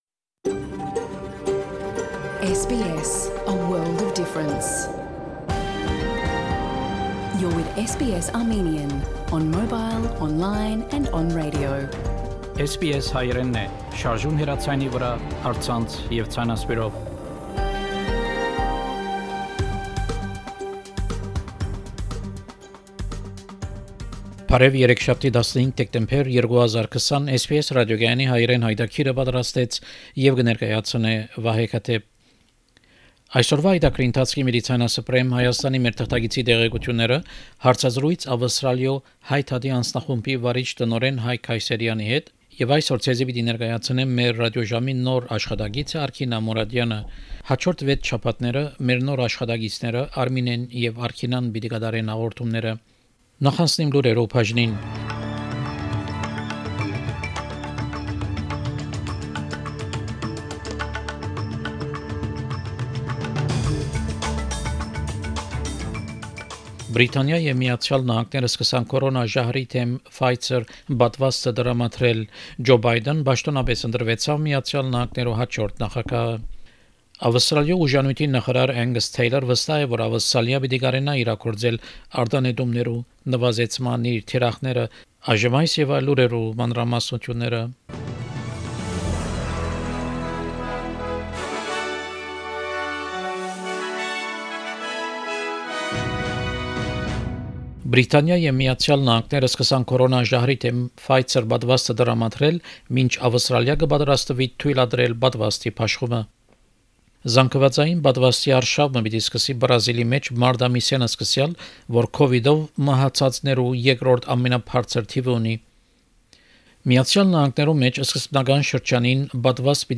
SBS Armenian news bulletin – 15 December 2020